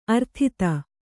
♪ arthita